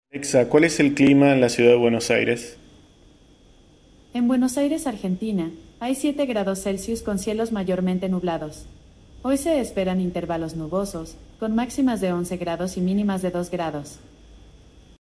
Veamos un ejemplo solicitando a Alexa que nos diga el clima de hoy: